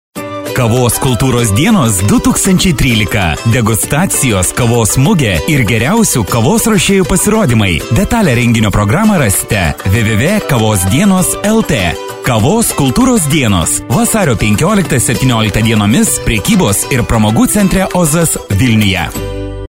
Lithuanian female voice over